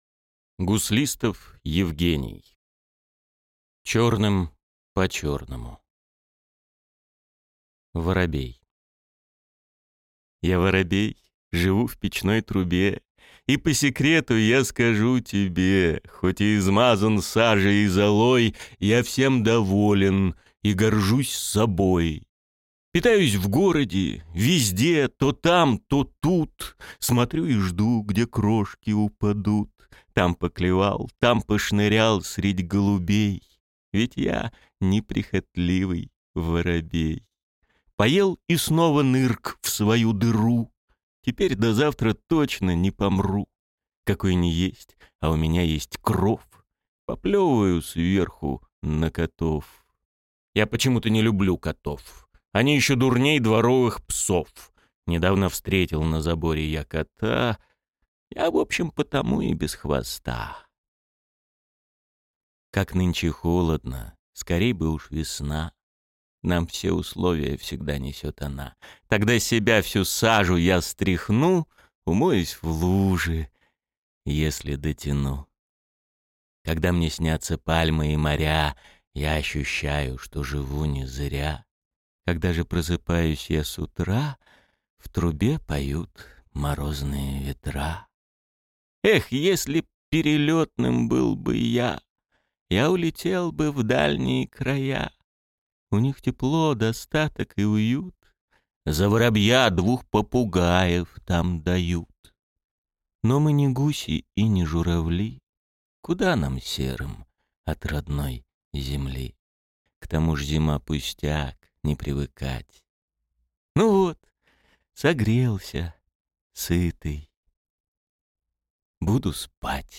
Аудиокнига Черным по черному | Библиотека аудиокниг